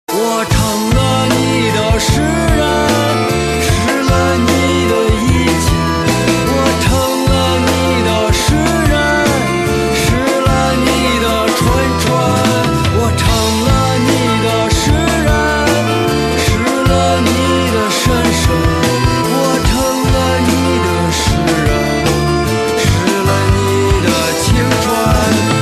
M4R铃声, MP3铃声, 华语歌曲 47 首发日期：2018-05-15 04:50 星期二